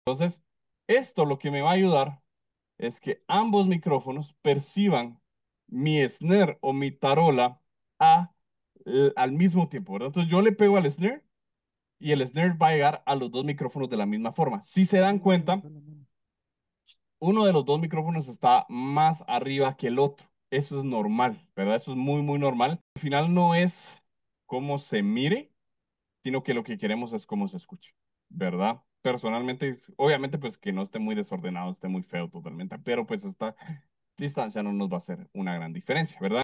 🥁 Hoy probamos los overheads para grabar la batería. El truco es que ambos micrófonos estén a la misma distancia de la tarola, así el golpe llega parejo a los dos y evitamos problemas de fase.
Queremos lograr un sonido balanceado, limpio y con la mejor definición posible.